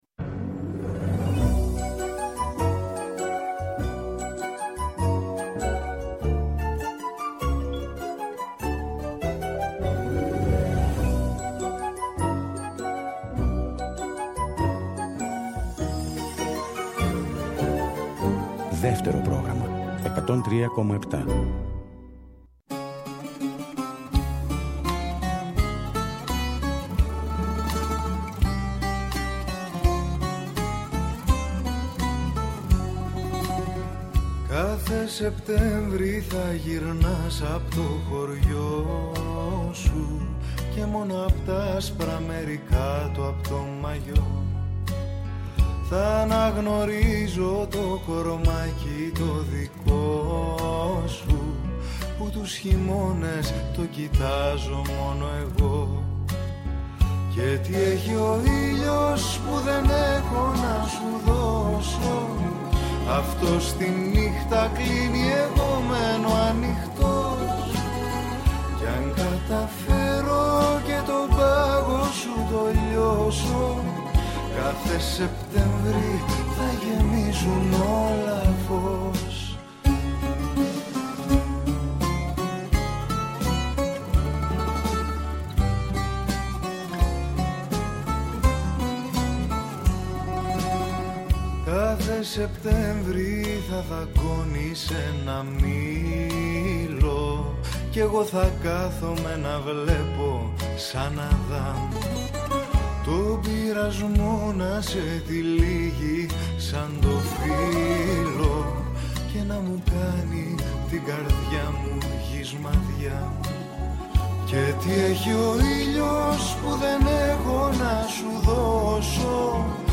Το χθεσινό μνημόσυνο, ένας χρόνος από το θάνατο του Μίκη Θεοδωράκη, διαμόρφωσε την σημερινή εκπομπή «Με μια αγκαλιά βιβλία», στο Β΄ πρόγραμμα.